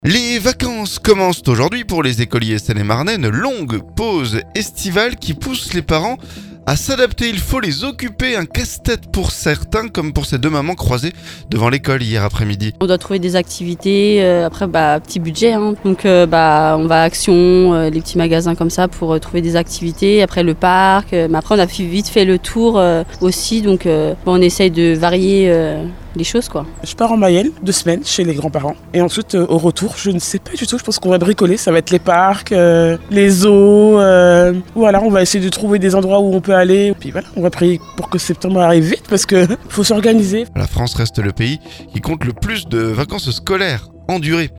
Les vacances commencent aujourd'hui pour les écoliers Seine-et-Marnais. Une longue pause estivale qui pousse les parents à s'adapter. Un casse-tête pour certains, comme pour ces deux mamans croisées devant l'école hier après-midi.